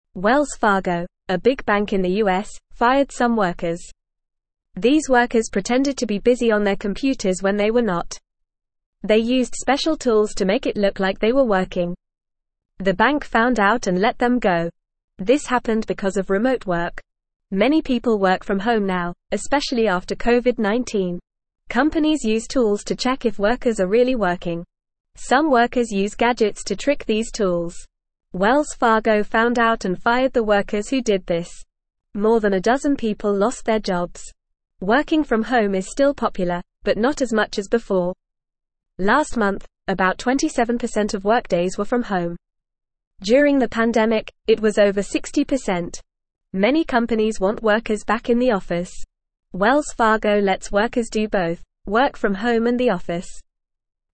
Fast
English-Newsroom-Beginner-FAST-Reading-Wells-Fargo-Fires-Workers-for-Pretending-to-Work.mp3